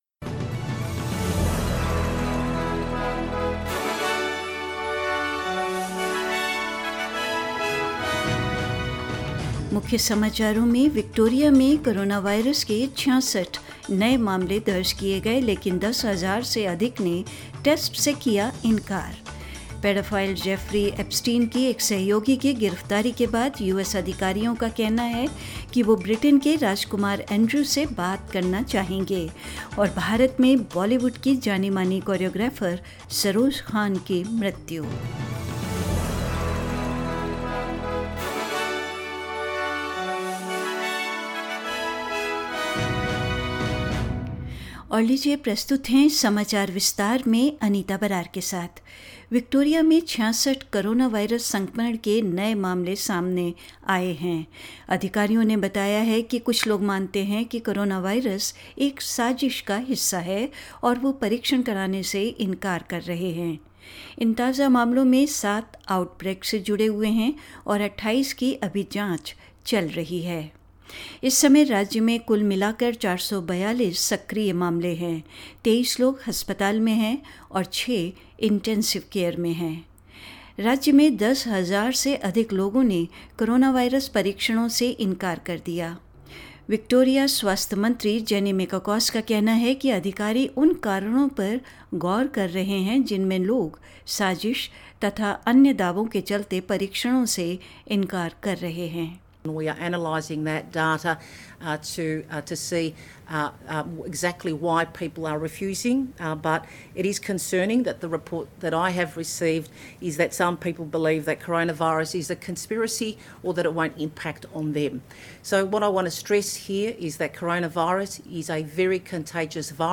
News in Hindi 3rd July 2020